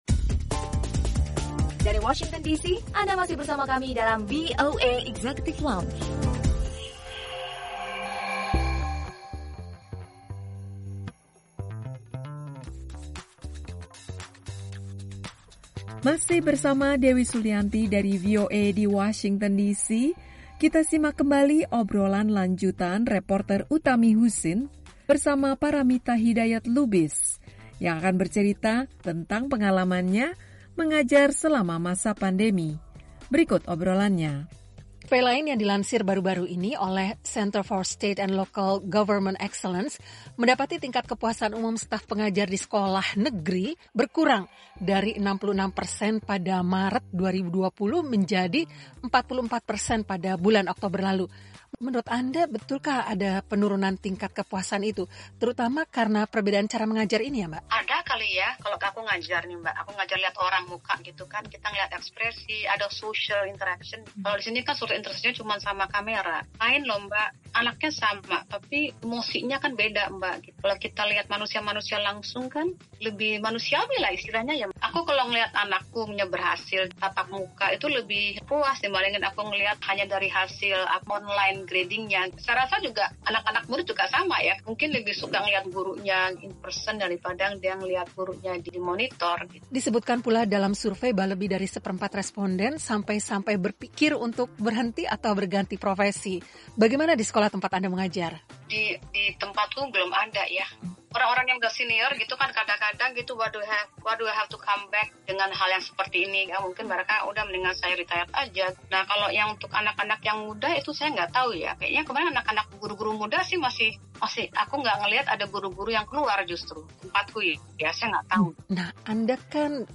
Obrolan lanjut